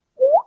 popup.ogg